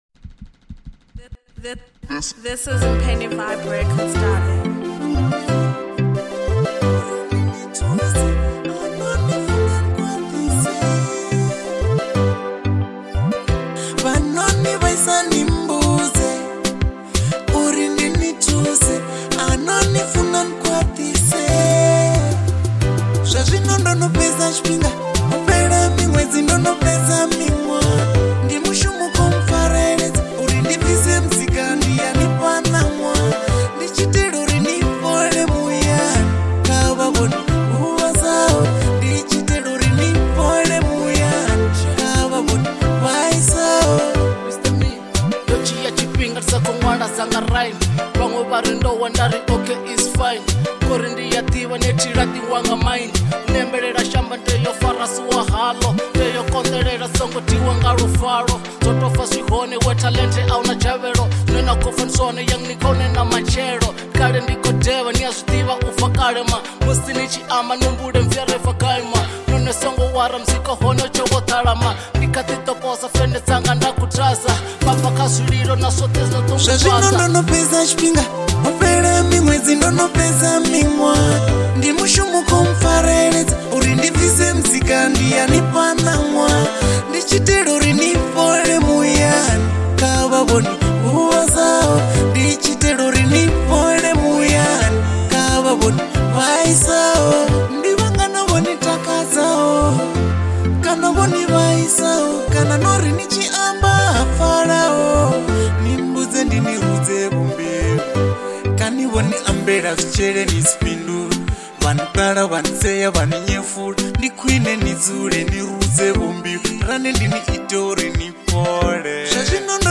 Genre : Acapella